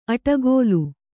♪ aṭagōlu